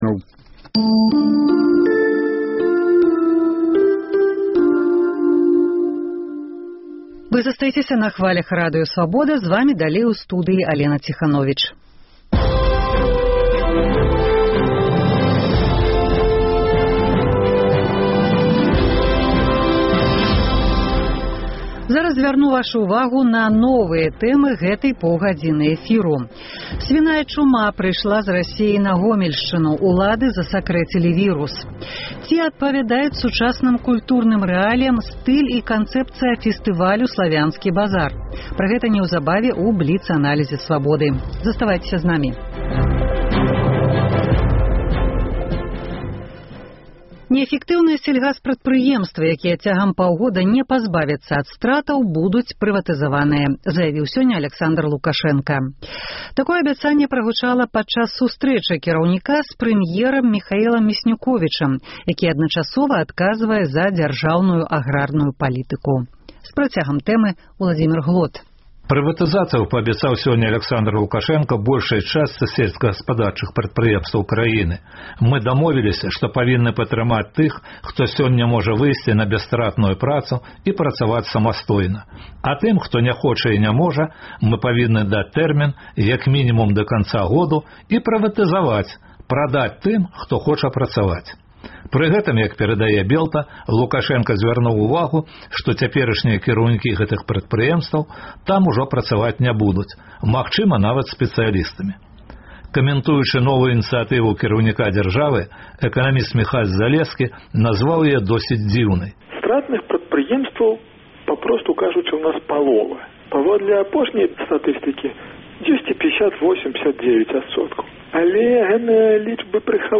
Наш карэспандэнт перадае адтуль, дзе ў 1986-м асадзілі радыяцыйную хмару. У Валожыне грамадзкія актывісты праводзяць пікет супраць будаўніцтва жылых дамоў на былым вайсковым стадыёне, месцы расстрэлу габрэяў. Рэпартаж зь месца падзеі.